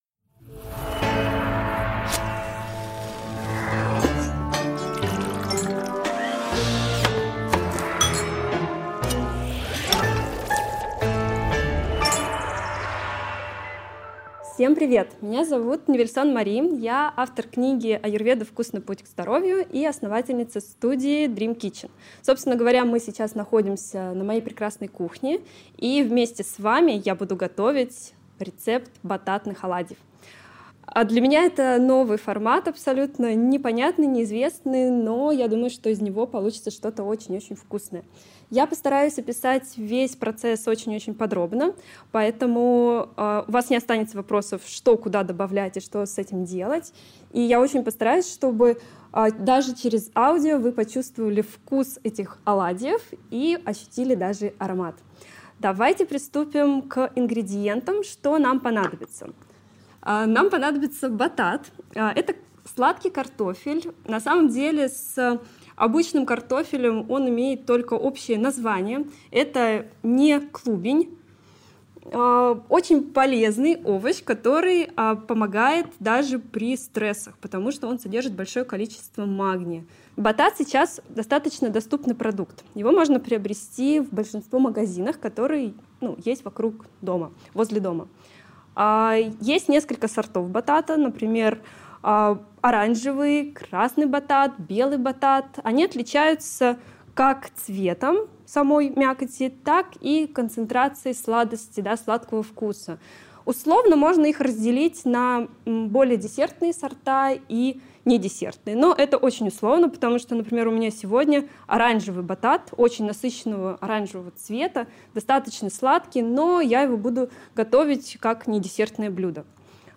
Аудиокнига Al dente: Оладьи из батата | Библиотека аудиокниг